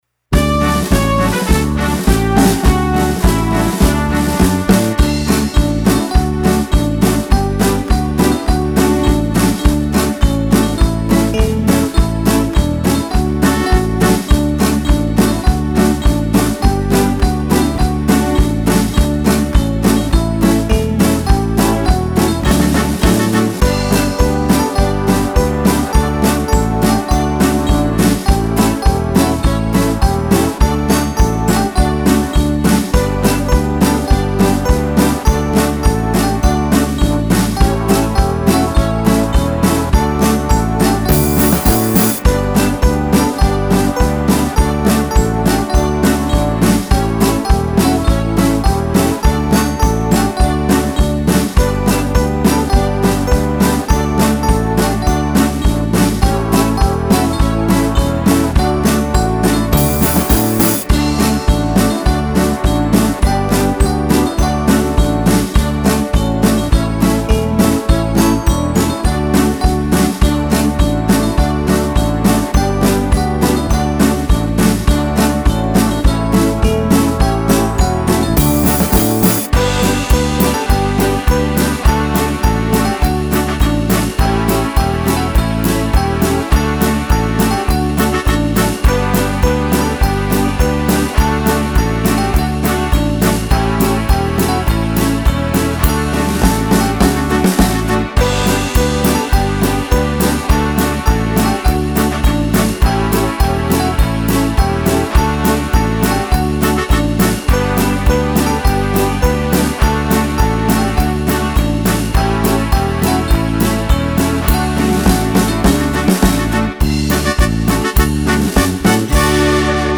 HYMN SZKOŁY
Święta Jadwigo - podkład z melodią
swieta_jadwigo_podklad_z_melodia.mp3